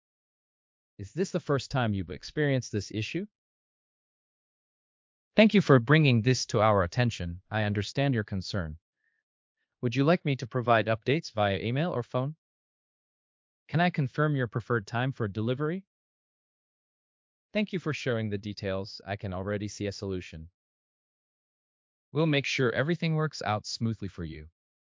Krisp’s Accent Conversion v3.7 model delivers significant improvements in naturalness, pronunciation accuracy, speaker similarity, voice stability, and audio clarity.
Filipino